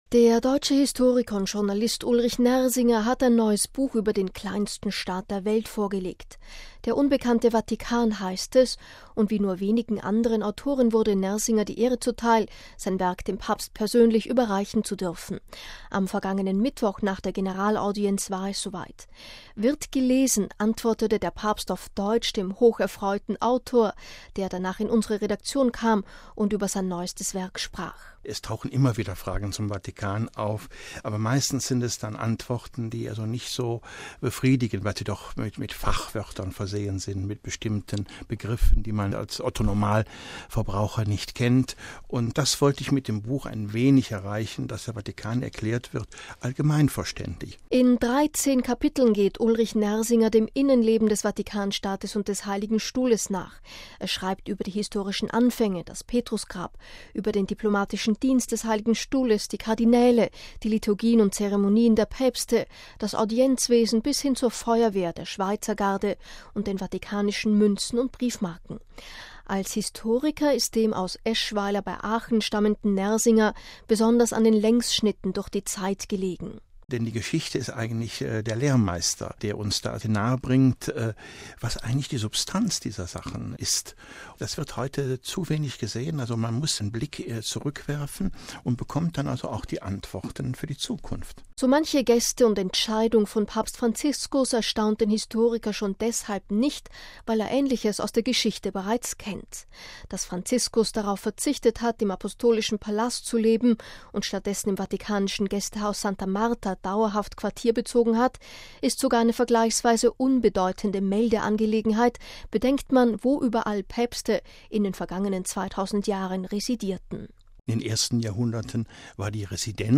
Eine Besprechung